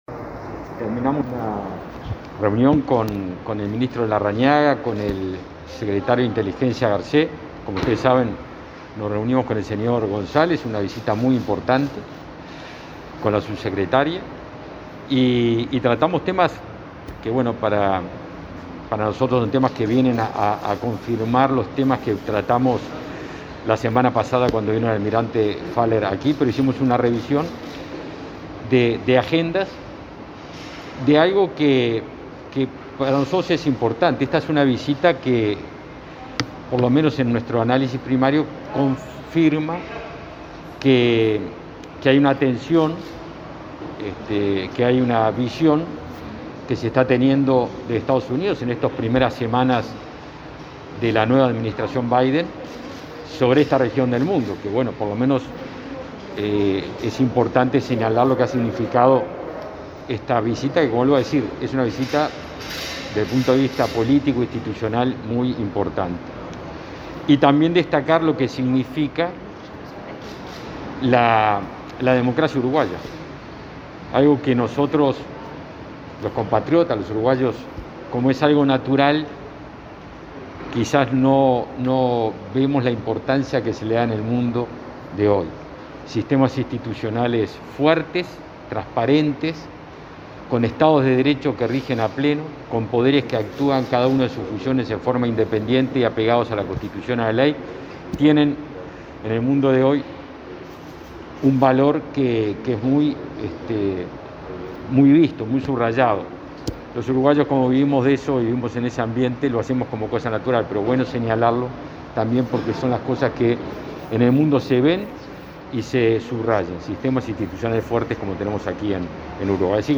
Declaraciones del ministro de Defensa, Javier García, y del director principal del Consejo de Seguridad Nacional de Estados Unidos, Juan González,